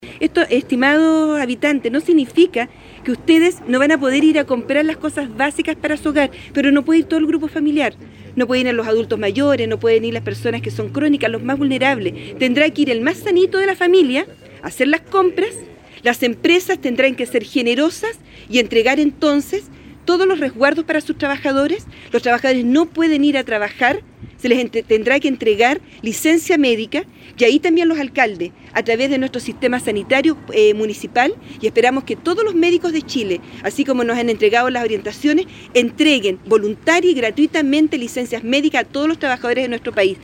A partir de las 00:00 horas de este sábado, se comienza  con las respectivas cuarentenas comunales, que consiste en una aislamiento que busca menos movimiento de personas por las calles, explico la alcaldesa: